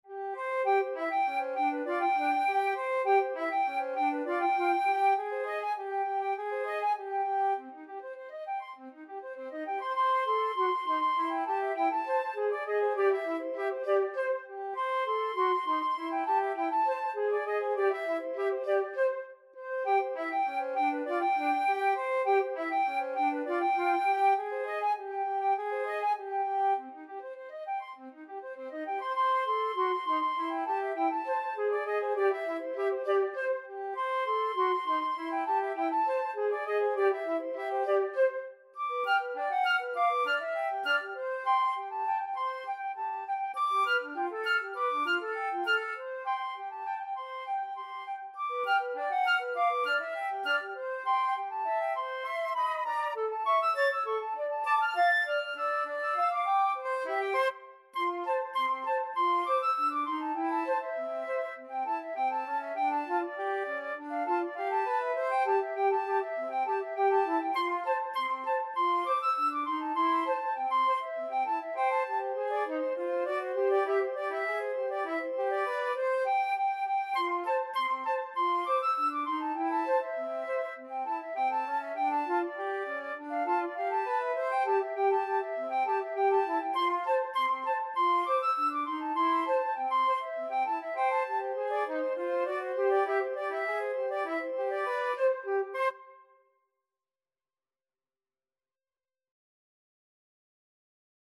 Energico =200
Flute Duet  (View more Intermediate Flute Duet Music)
Jazz (View more Jazz Flute Duet Music)